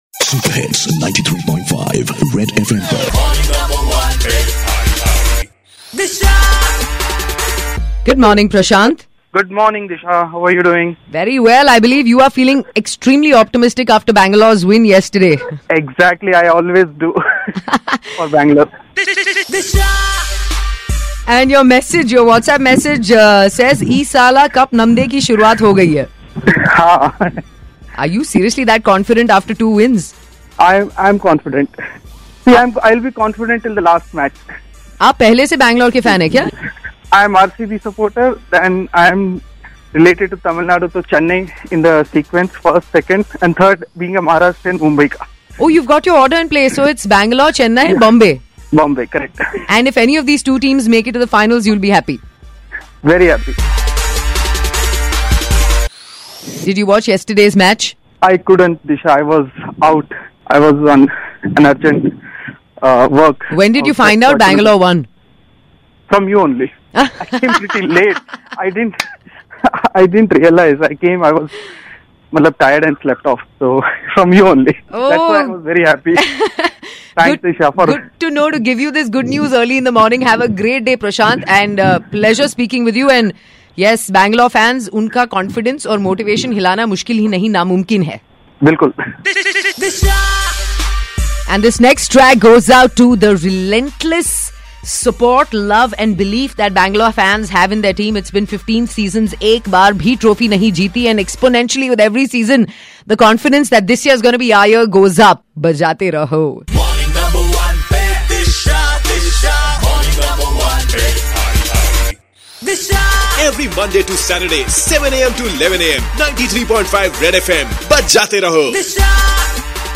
talks to listener